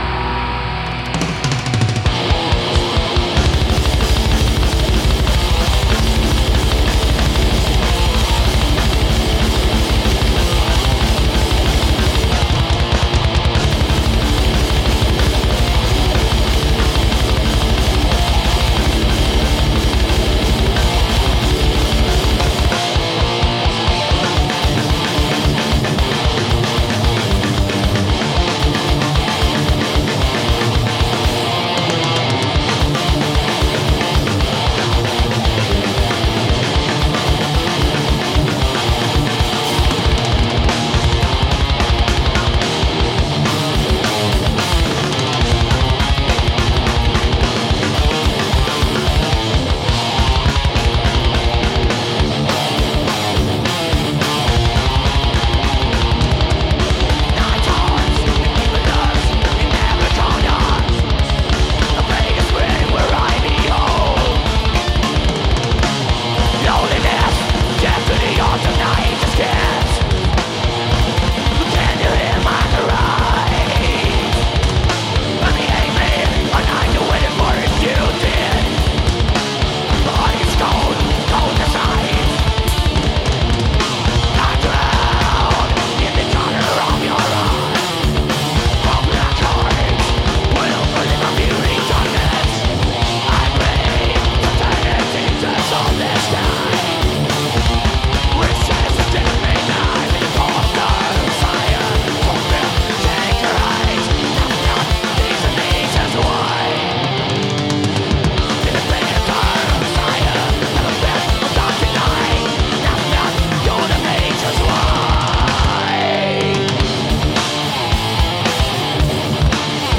Live
Metal